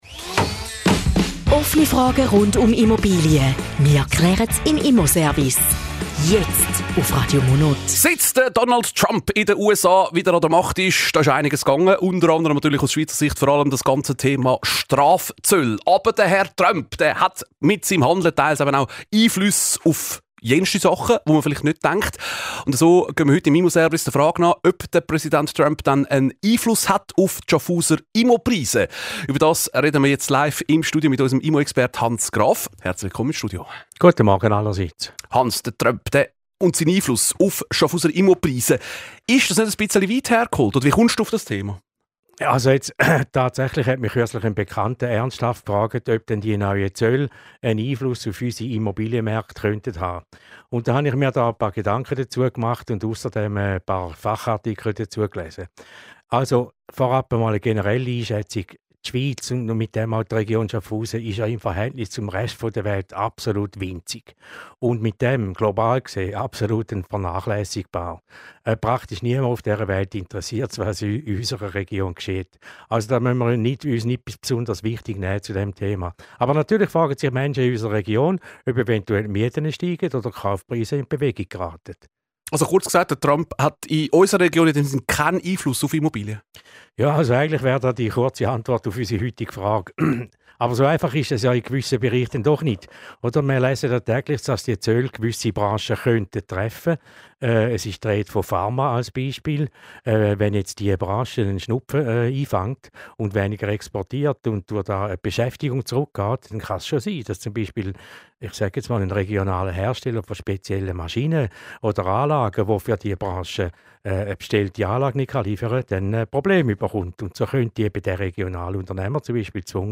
Zusammenfassung des Interviews zum Thema "Trump und die Immobilienpreise":